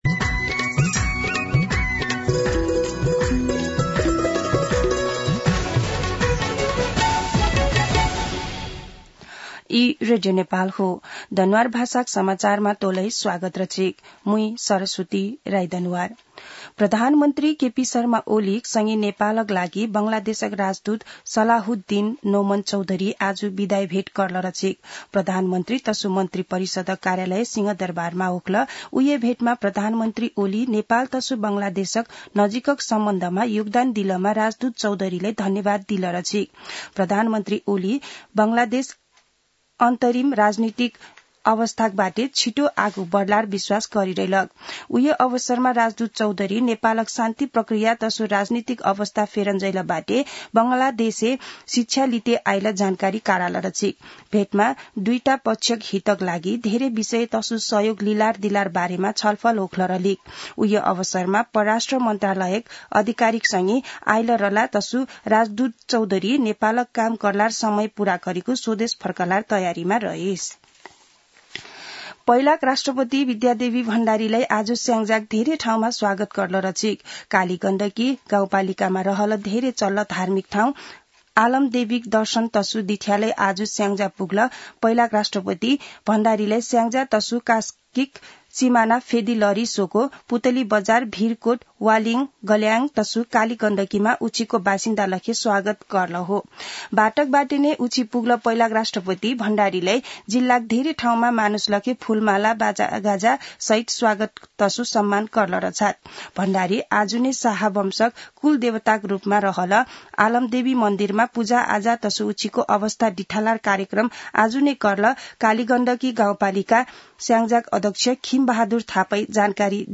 दनुवार भाषामा समाचार : ६ पुष , २०८१
Danuwar-news-1.mp3